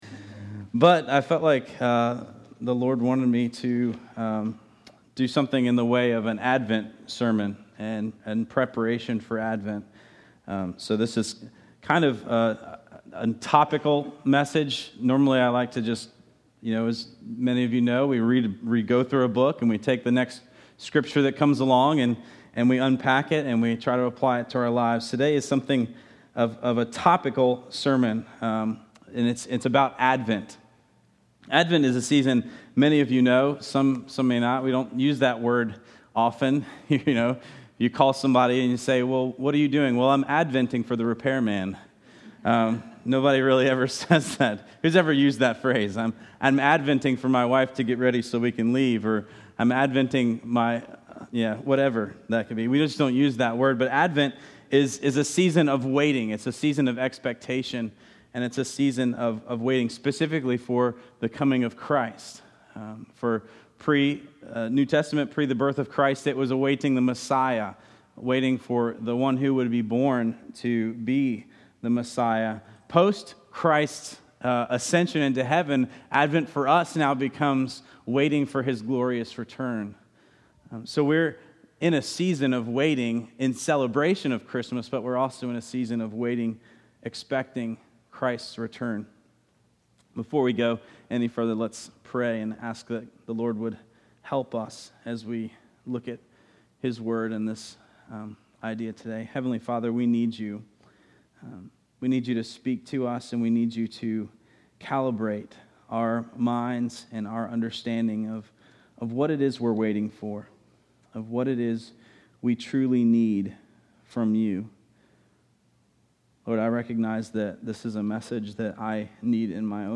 Break from Ephesians, and expositional style. Topical Sermon.